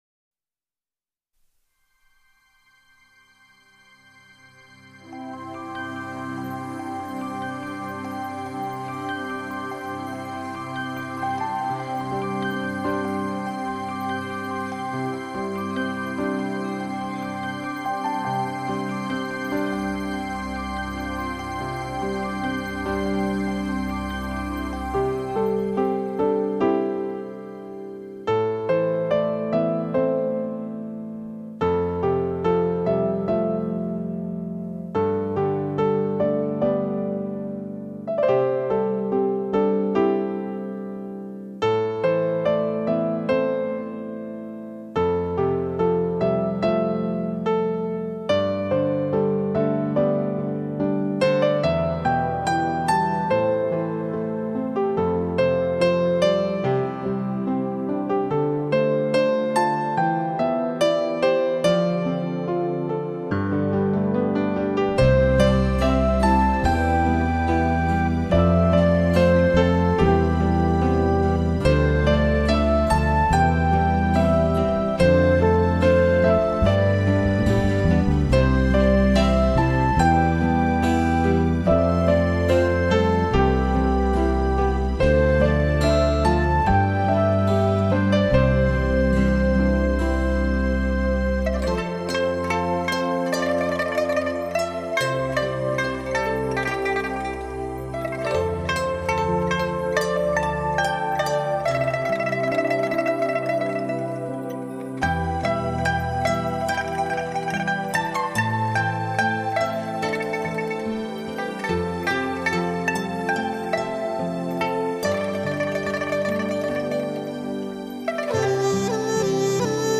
系列名称：大自然系列 珍爱台湾系列
大小提琴诠释了蝴蝶生态的知性；二胡琵琶表现了庄子梦境的感性；笛子琵琶演释了梁祝爱情的浪漫；长笛排笛吹奏出蝴蝶快乐的飞舞。